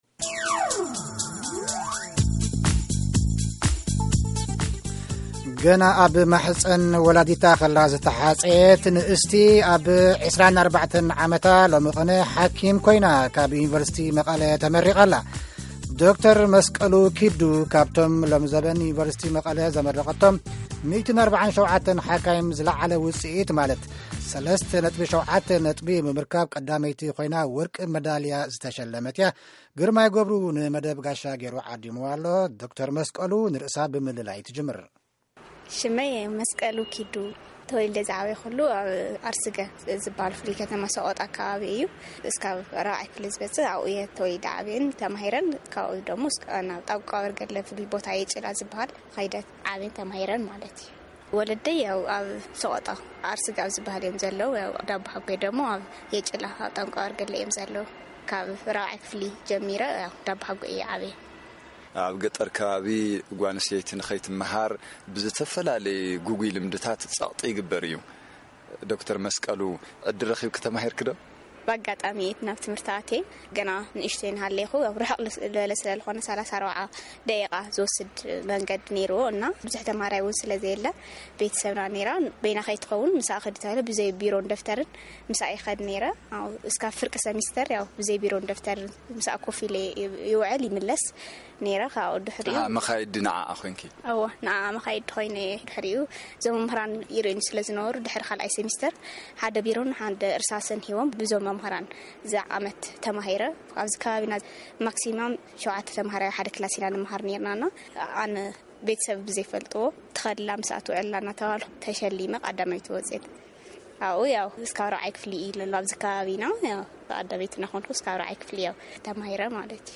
ንቃለ-መጠይቅ